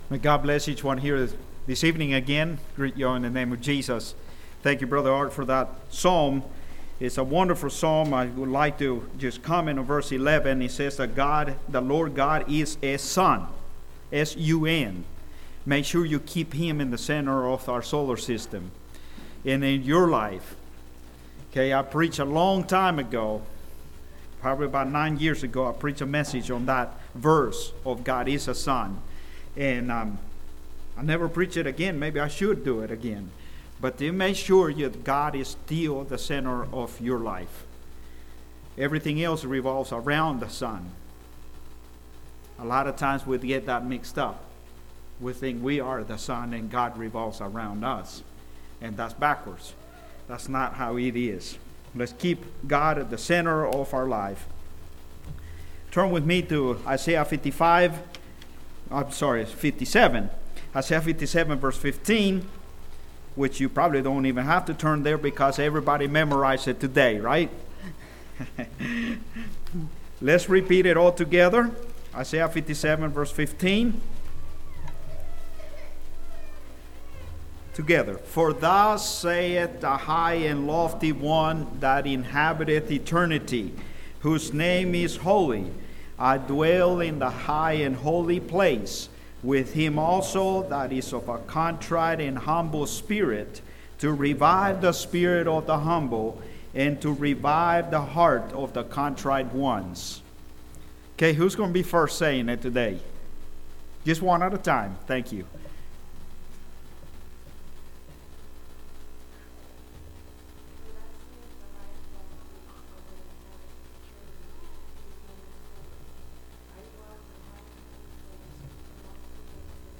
Congregation: Great Lakes Speaker